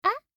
알림음 8_BoyAh1.mp3